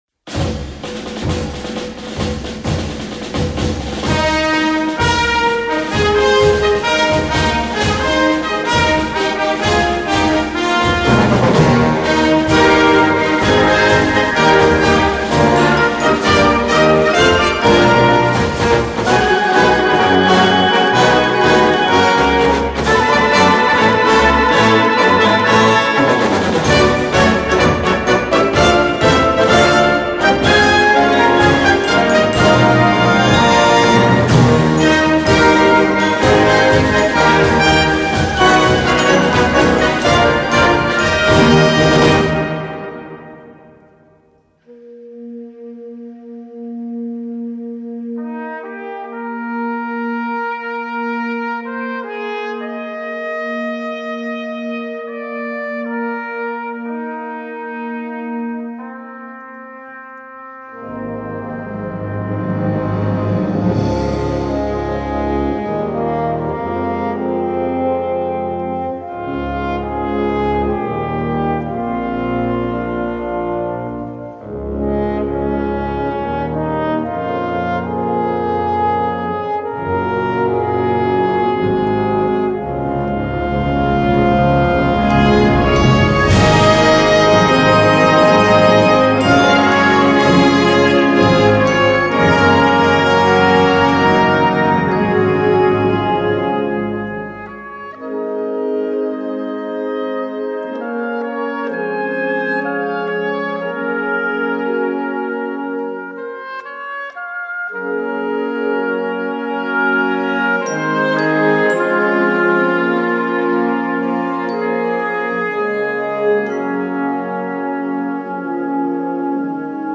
• View File Orchestre d'Harmonie
• View File Brass Band